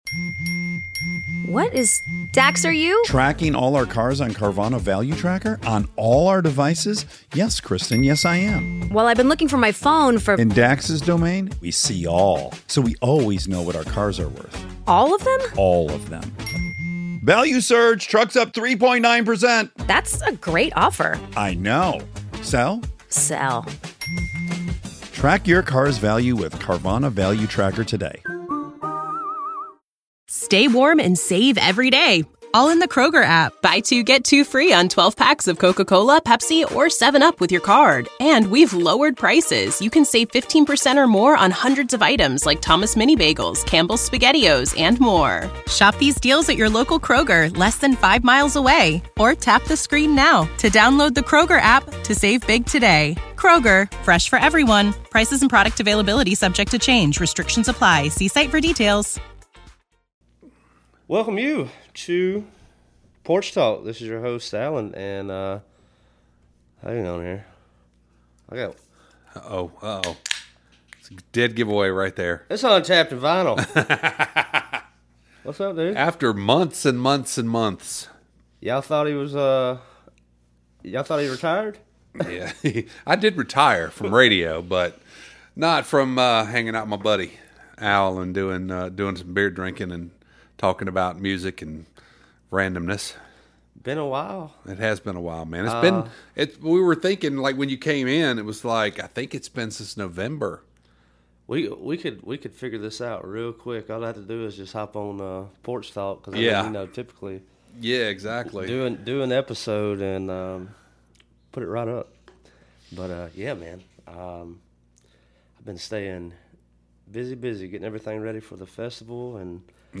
candid conversations with creatives